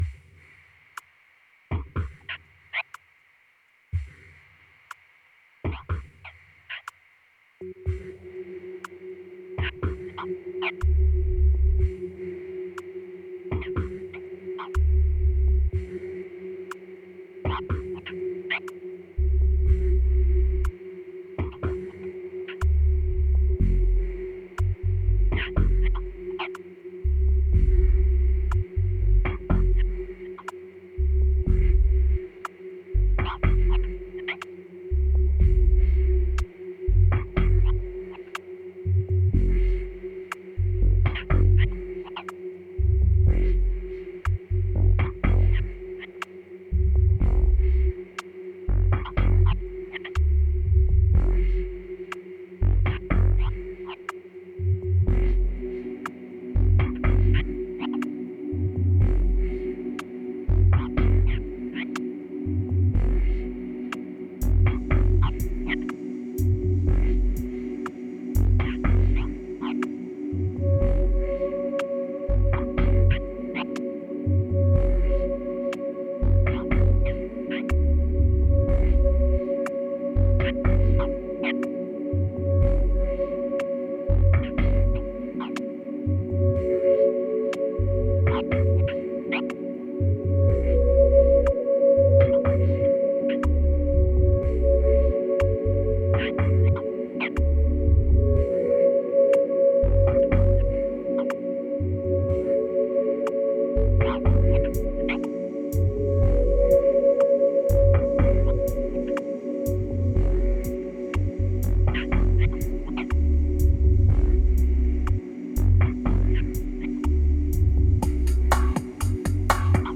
2551📈 - 65%🤔 - 122BPM🔊 - 2016-03-26📅 - 490🌟